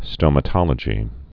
(stōmə-tŏlə-jē)